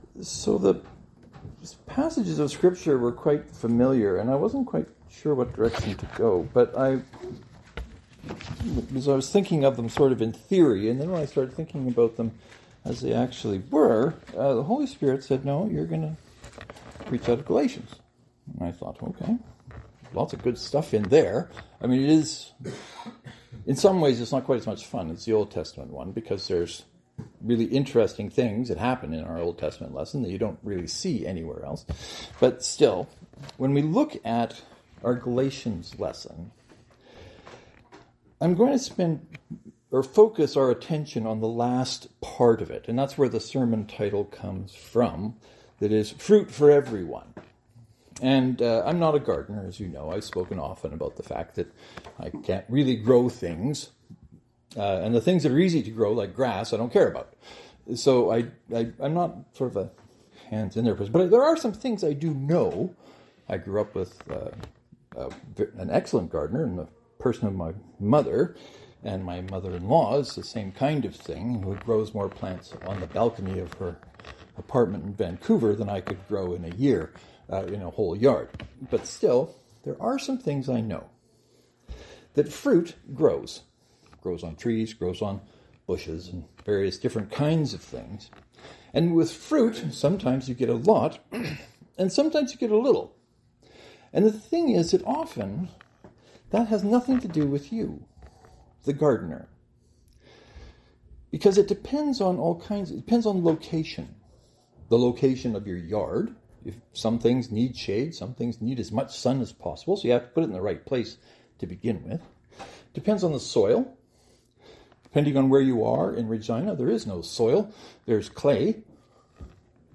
The sermon title is intentionally ambiguous. “Fruit for everyone!” can refer to the fruit of the Spirit within those who have a relationship with God.